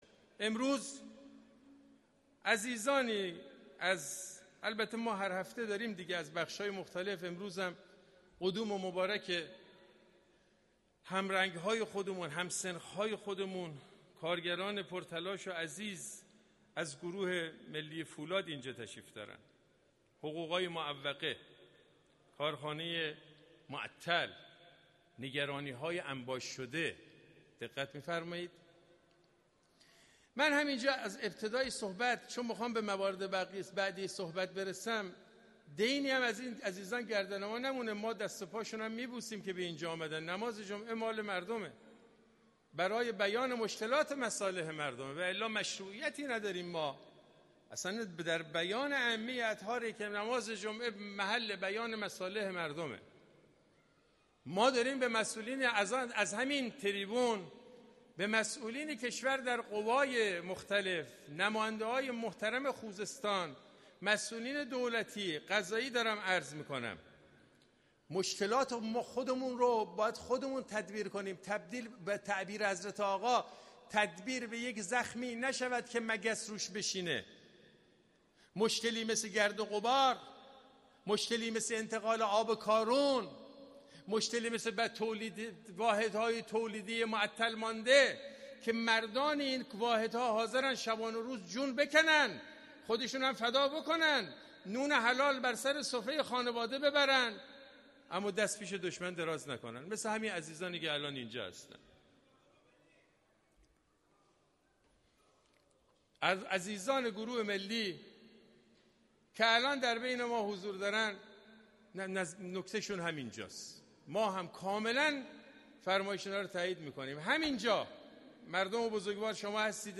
در اواسط خطبه دوم، یکی از کارگران که در صفوف ابتدایی هم نشسته بود، با صدای بلند نکاتی را در خصوص مشکلاتشان مطرح کرد که عملا باعث توقف خطبه های نمازجمعه شد، یکی از پرسنل ستادنمازجمعه به سمت وی رفت تا او را دعوت به آرامش کند اما خطیب جمعه از او خواست به آن کارگر فرصت بدهد تا با آرامش مطالبش را مطرح کند.